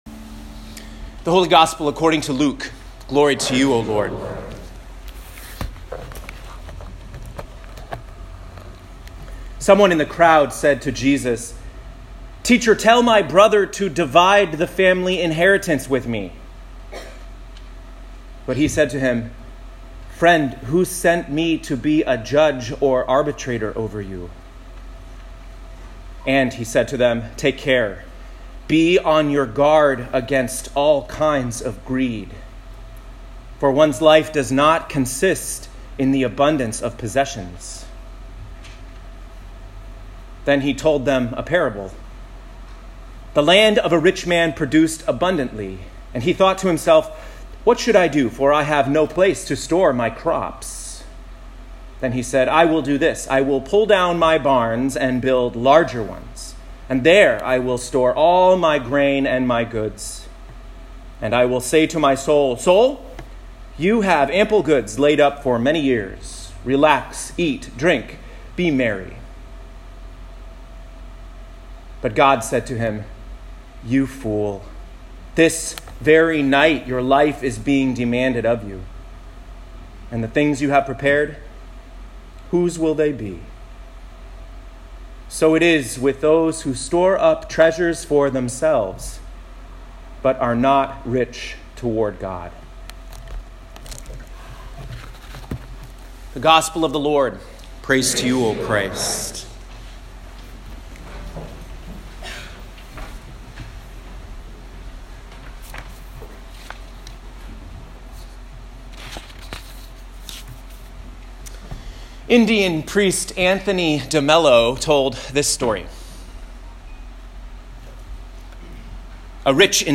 Eighth Sunday after Pentecost, Year C (8/4/2019)
Home › Sermons › Enough is Enough